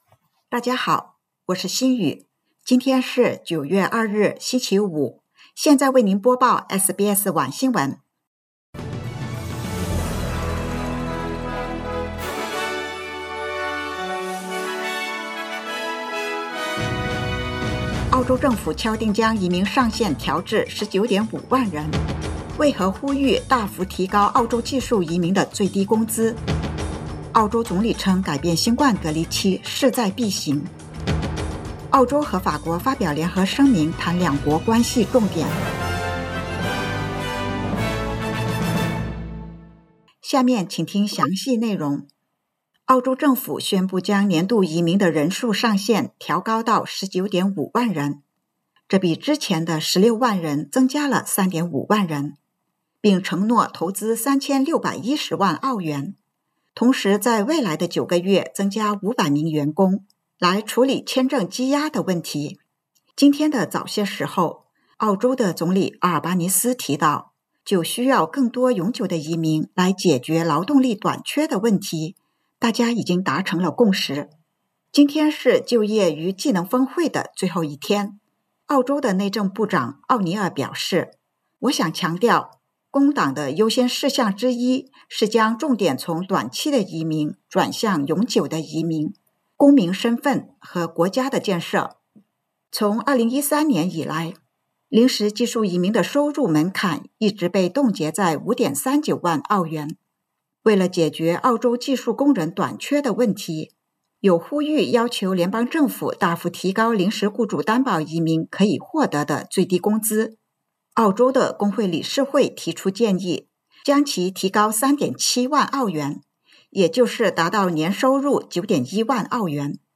SBS晚新闻（2022年9月2日）